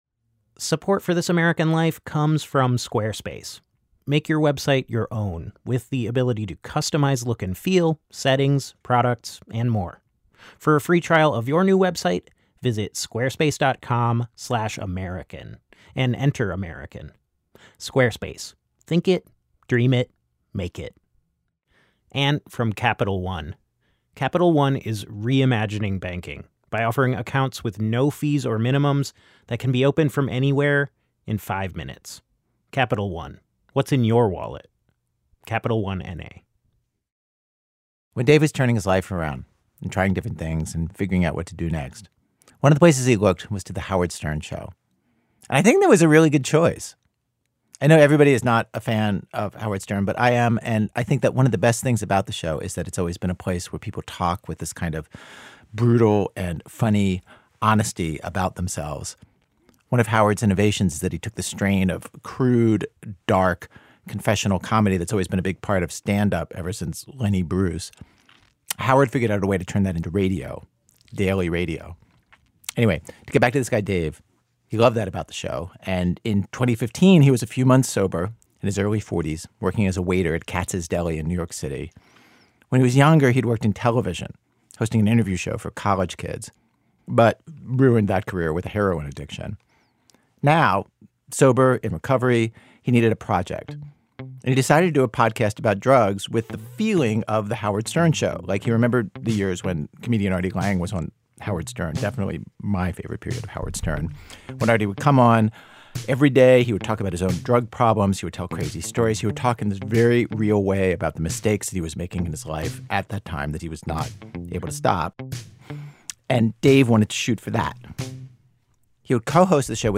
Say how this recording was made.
Each came from a DIY radio outfit.